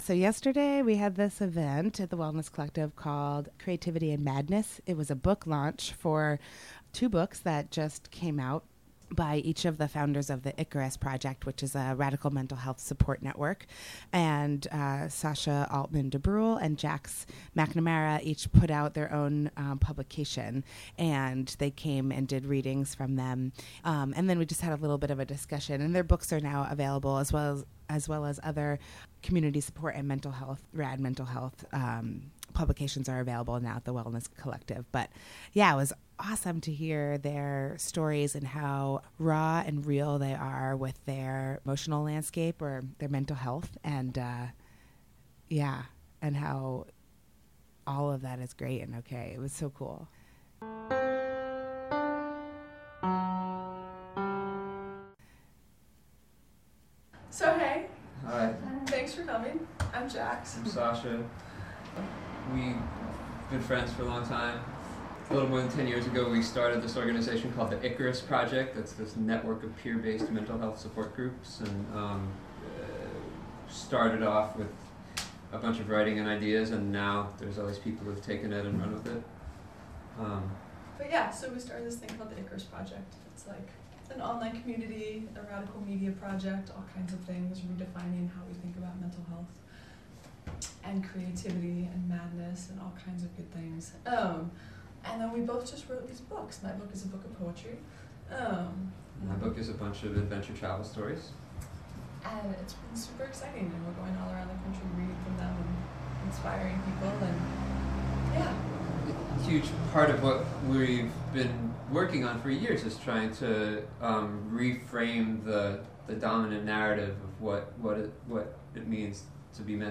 Interviewed
a recorded segment from the book launch